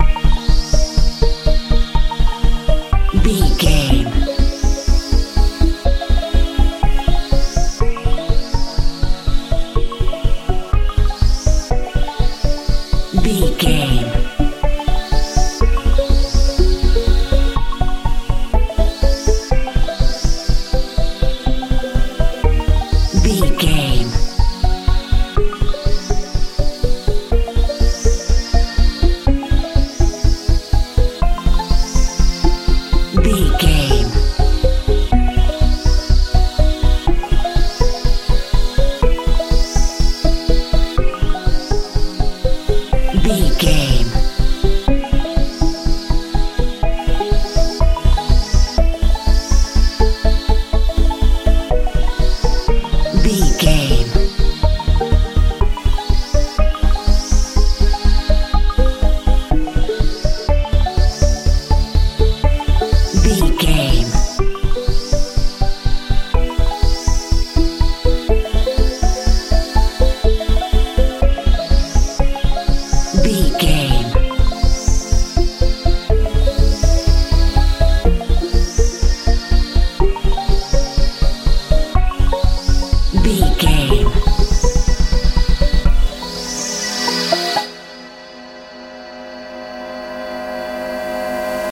pop dance
Aeolian/Minor
E♭
magical
mystical
bass guitar
synthesiser
hypnotic
haunting
dreamy